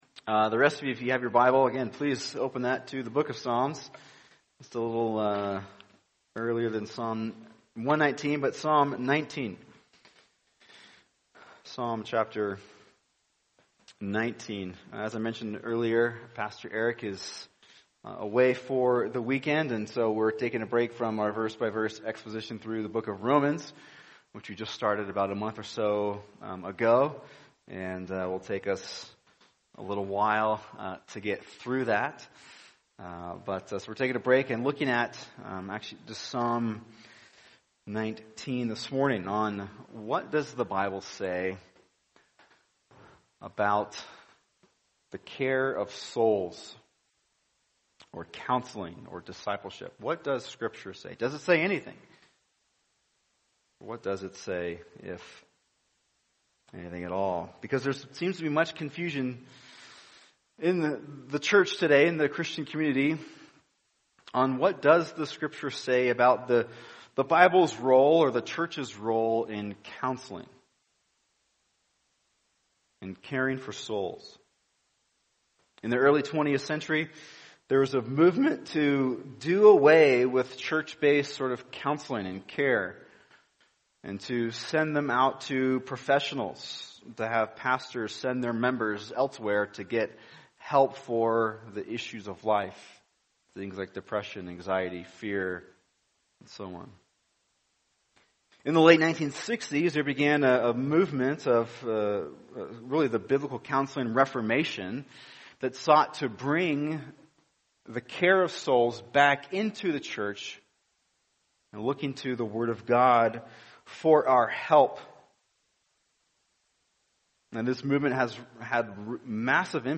[sermon] Psalm 19 The Bible and Counseling | Cornerstone Church - Jackson Hole